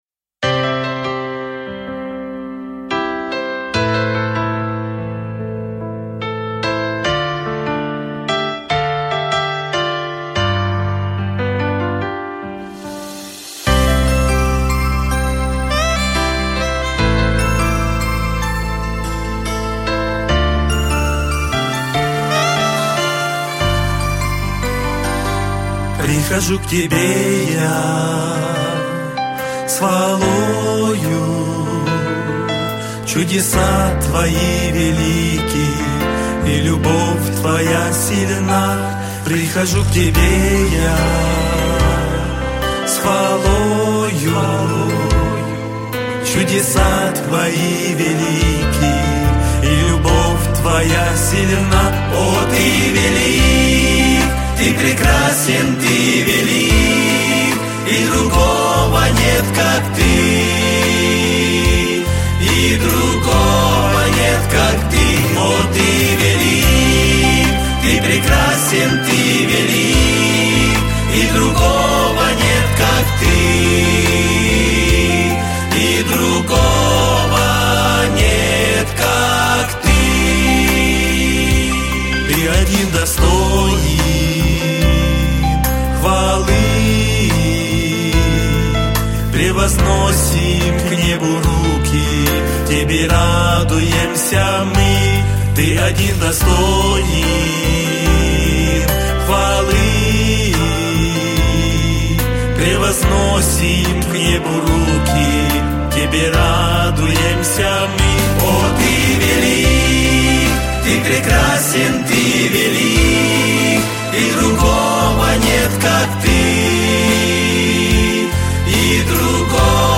929 просмотров 1414 прослушиваний 101 скачиваний BPM: 144